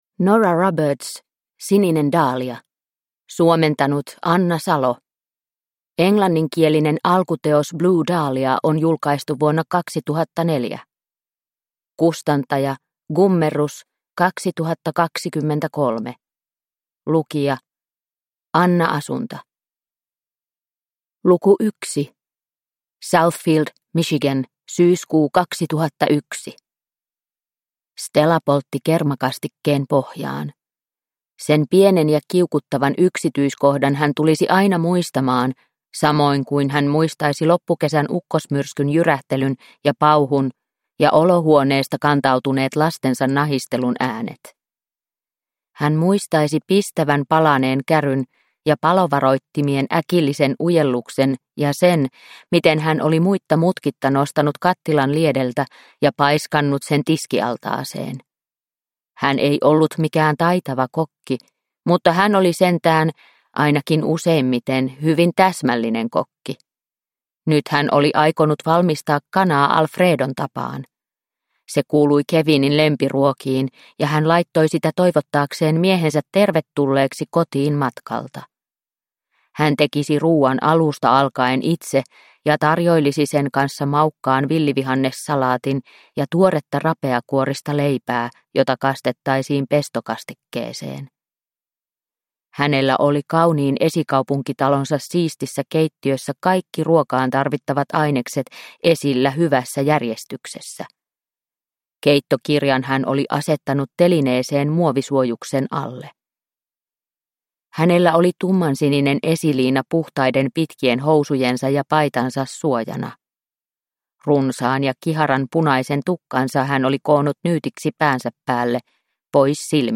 Sininen daalia – Ljudbok – Laddas ner